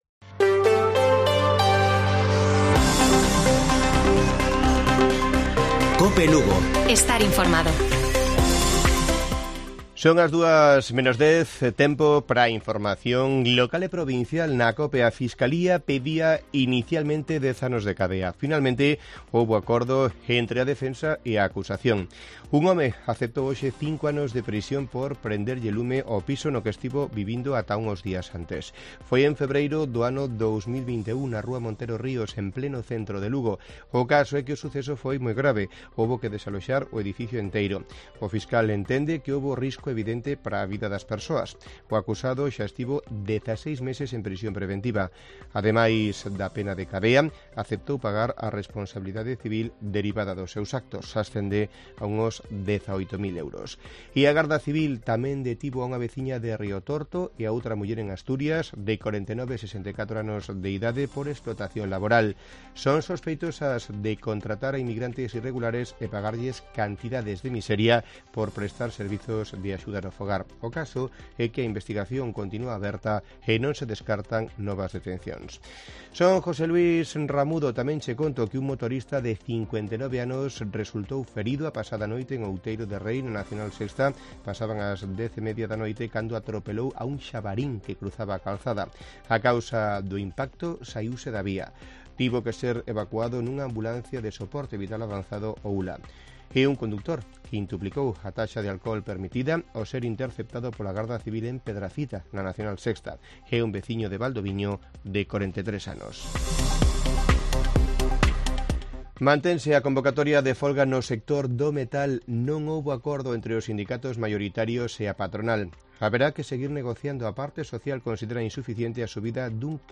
Informativo Mediodía de Cope Lugo. 09 DE MAYO. 13:50 horas